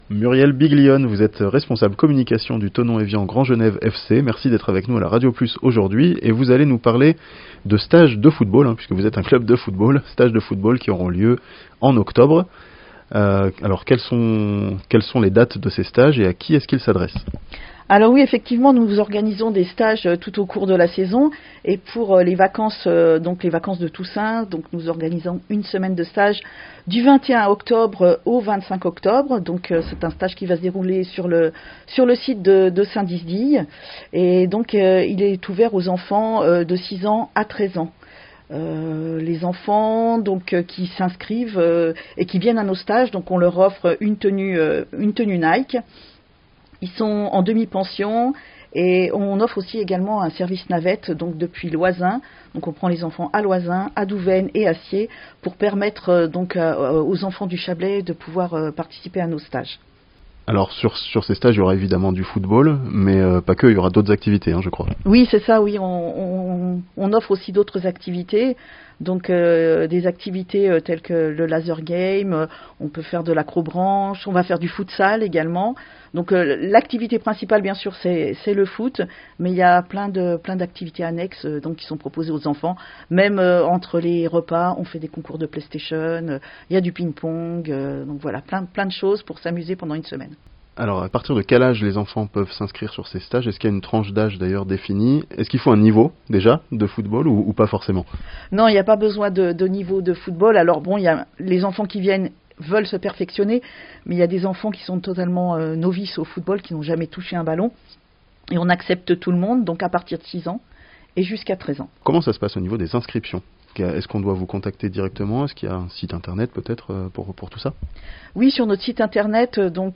Le Thonon Evian Grand Genève FC organise un stage de football pour les enfants (interview)